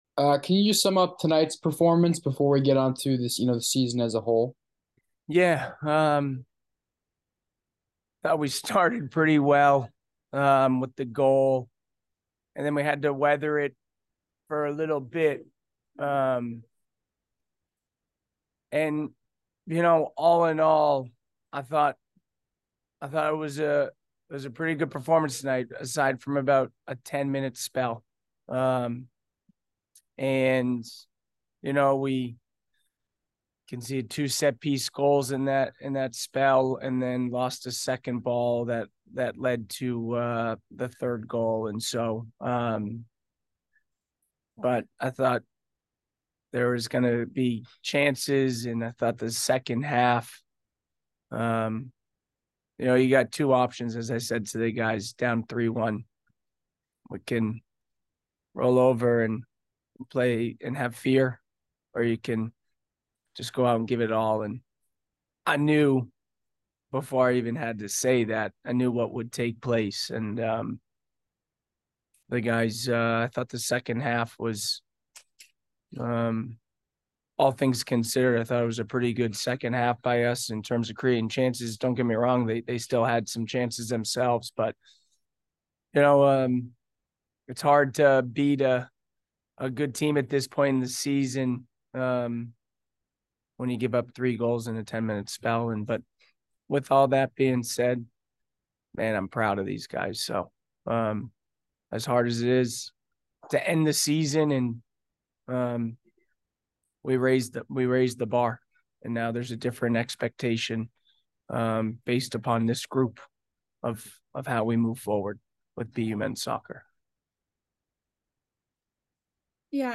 Men's Soccer / Syracuse Postgame Interview (11-16-23)